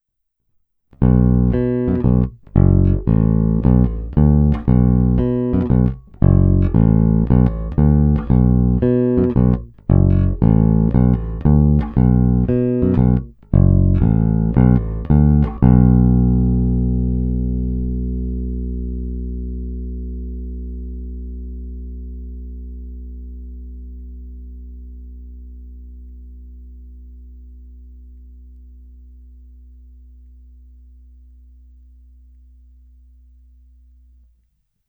Zvuk je tlustý, zvonivý vrčák s okamžitým nástupem pevného tónu.
Není-li uvedeno jinak, následující nahrávky jsou provedeny rovnou do zvukovky a dále kromě normalizace ponechány bez úprav.
Tónová clona vždy plně otevřená.
Hra mezi snímačem a kobylkou